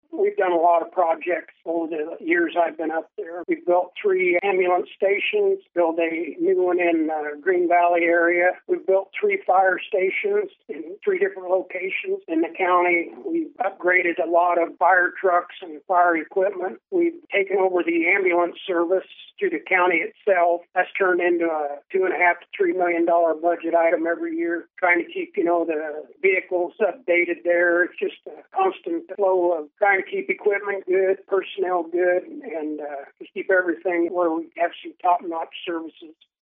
KMAN spoke with Weixelman about his motivation for running for reelection and his stances on various county issues.